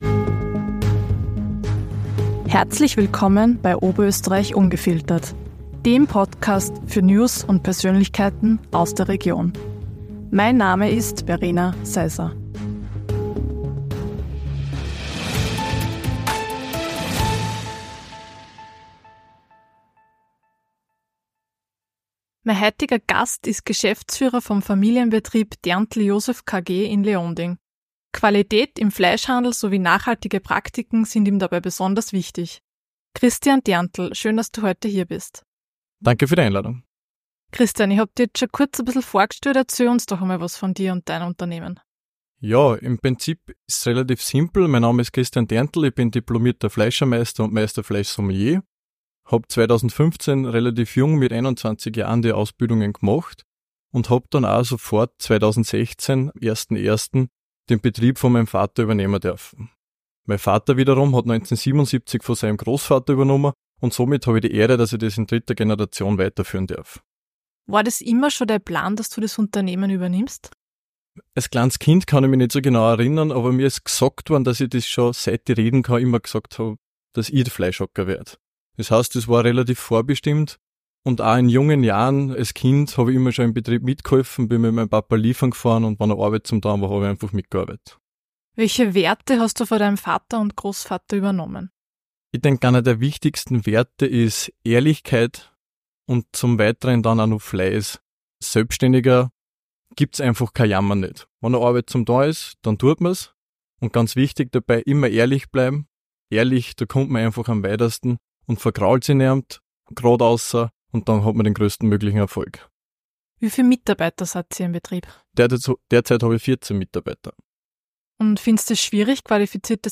Nachhaltigkeit und Qualität - Ein Gespräch mit dem Fleischermeister ~ OÖ ungefiltert Podcast